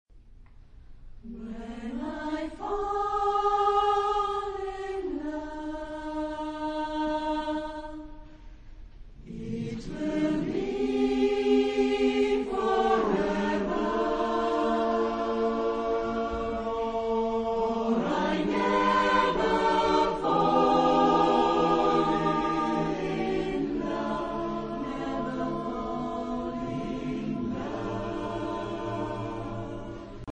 SATB (4 voices mixed).
: 7. Deutscher Chorwettbewerb 2006 Kiel